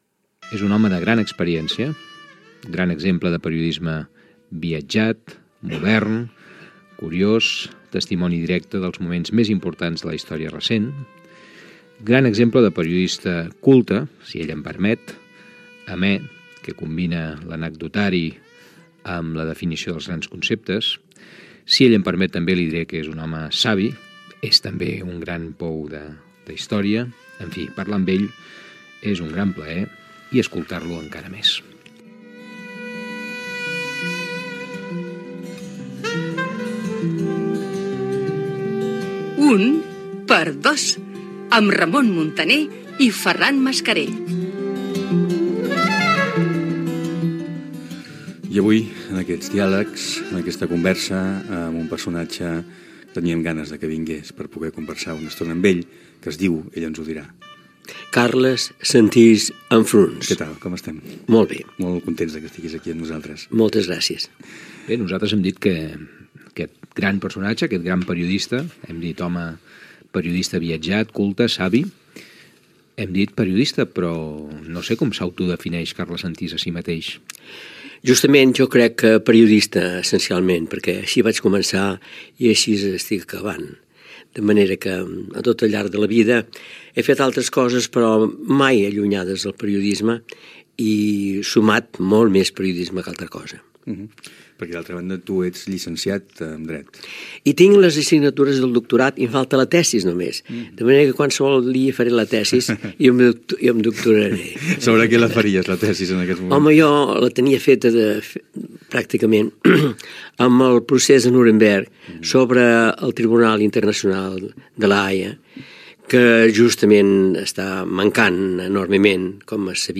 Presentació del periodista Carles Sentís, indicatiu del programa, entrevista a l'invitat del programa